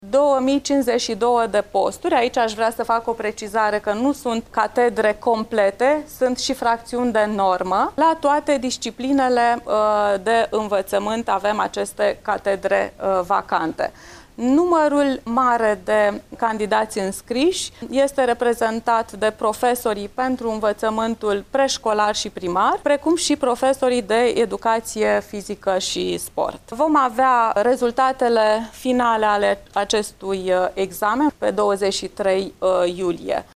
La Iaşi s-au înscris 1520 de candidaţi după cum a precizat Genoveva Farcaş, şefa Inspectoratului Şcolar Judeţean: